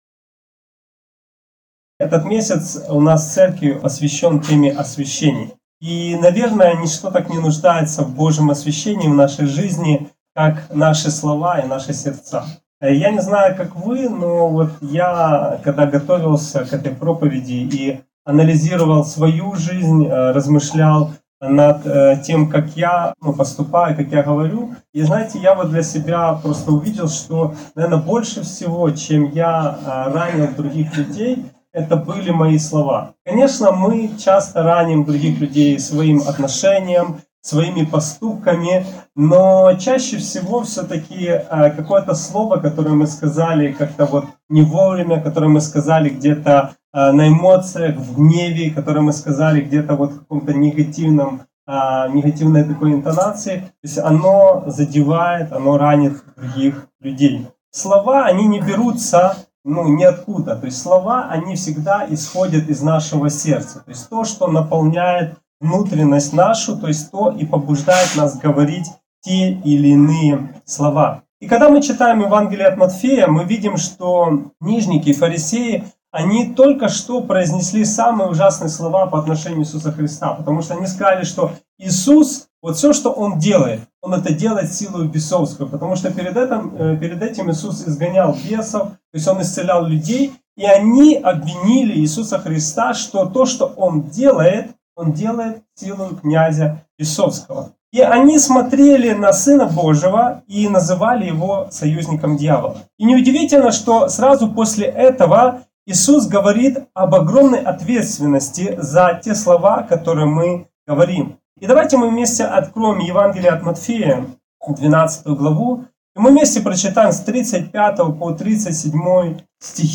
Актуальна проповідь